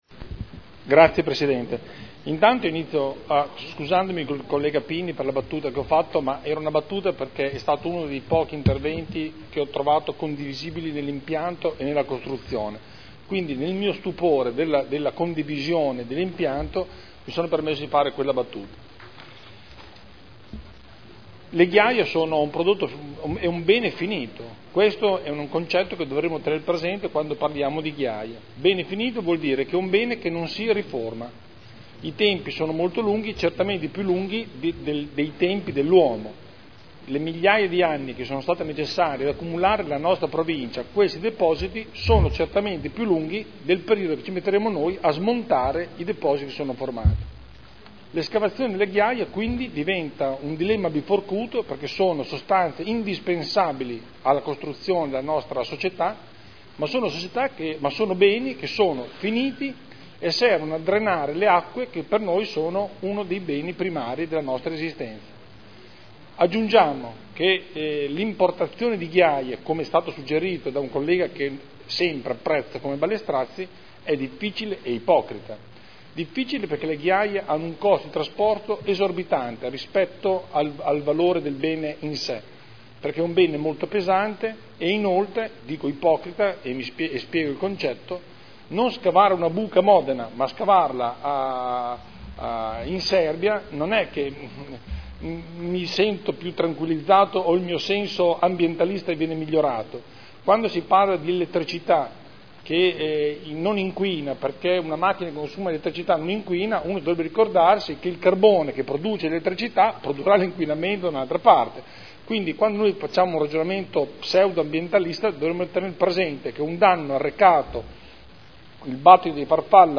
Seduta del 14/07/2011. Dibattito. Delibera: Approvazione dell’atto di indirizzo per l’attuazione del Piano delle Attività Estrattive del Comune di Modena (Commissione consiliare del 28 giugno 2011)